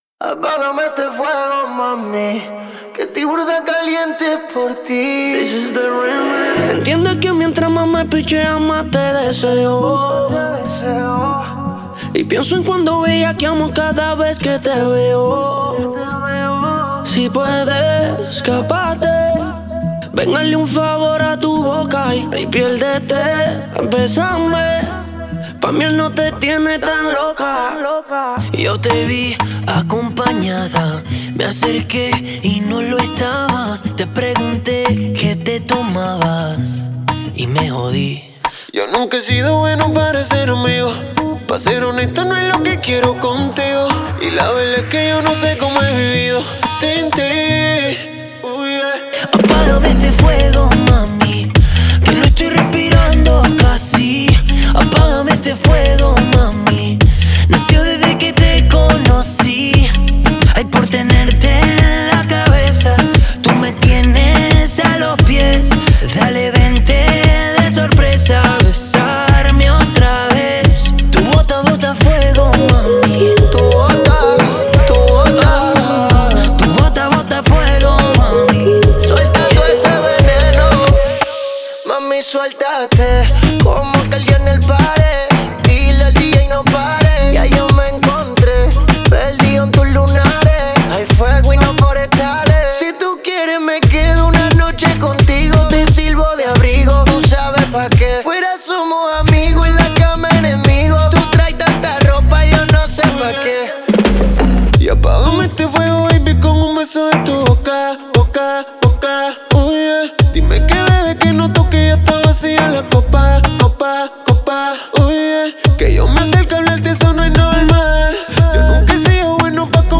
• Жанр: Арабские песни